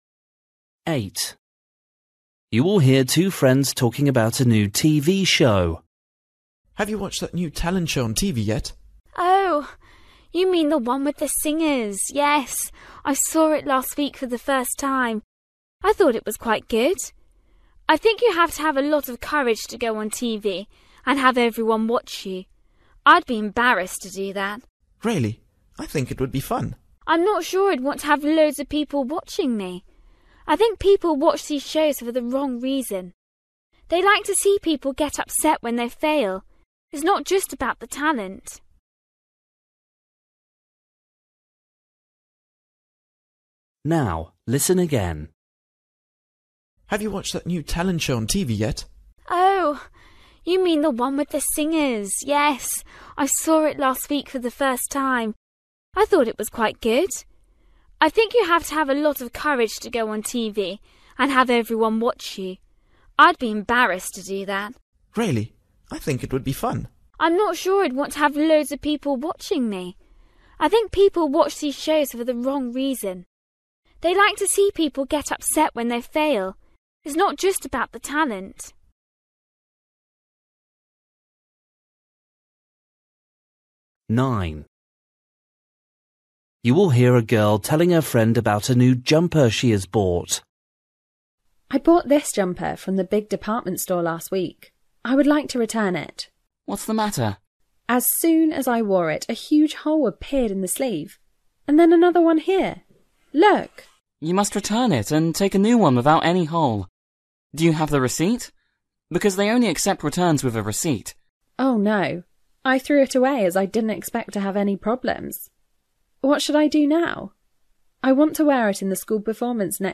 Listening: everyday short conversations
8   You will hear two friends talking about a new TV show.
9   You will hear a girl telling her friend about a new jumper she has bought.
10   You will hear a girl telling a friend about a camping trip.